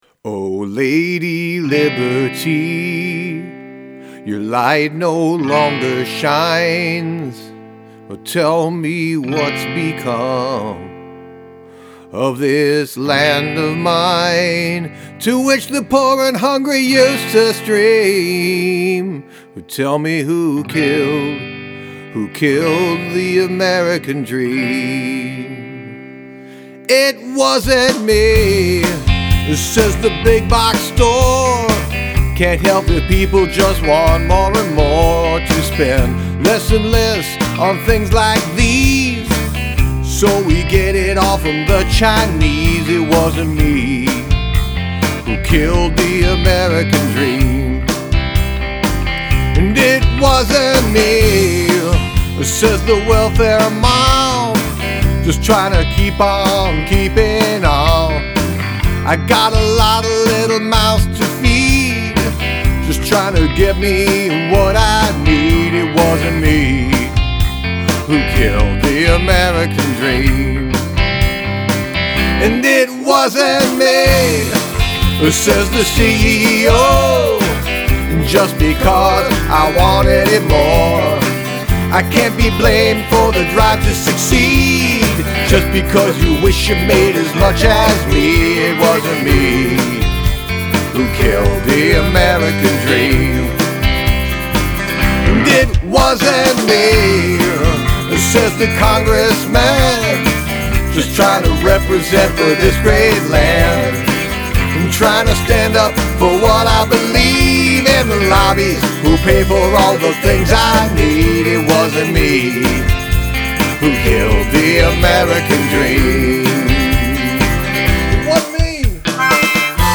Multiple Narrators